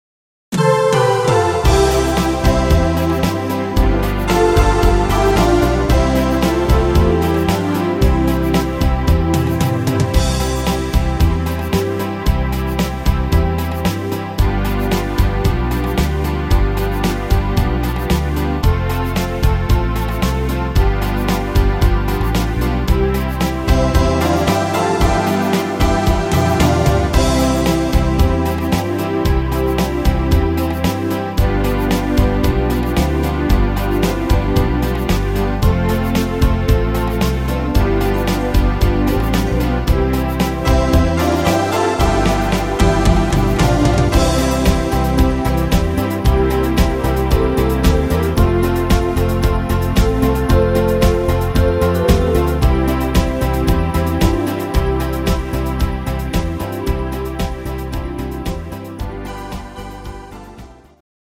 Rhythmus  Beguine
Art  Volkstümlicher Schlager, Deutsch